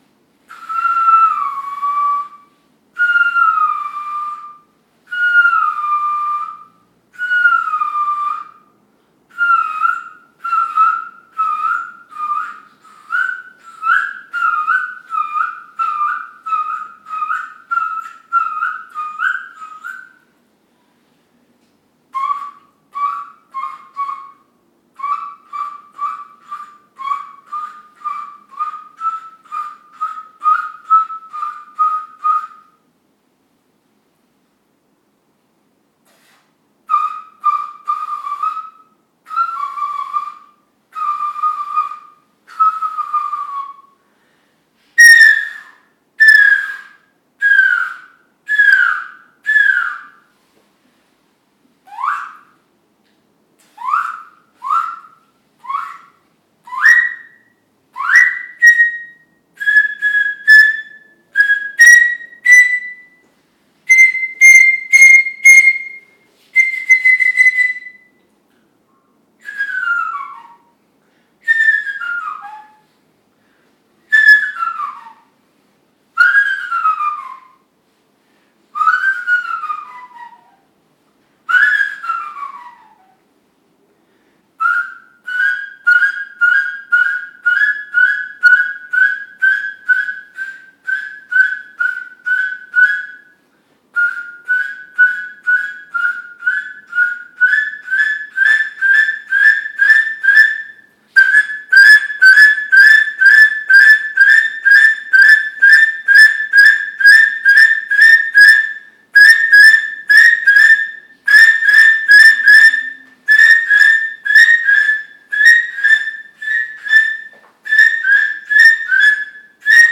El sonido de esta pieza está determinado por su tubo cónico, el cual permite variaciones de timbre y altura en función de la posición del soplido. Sus agudos sutiles y las leves variaciones tonales dadas por el destape del agujero de digitación, producen un característico timbre ornitofónico.
Pivulka ornitomorfa. Audio
Aerófonos de piedra precolombinos Descargar 2957.3kb